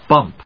/bˈʌmp(米国英語)/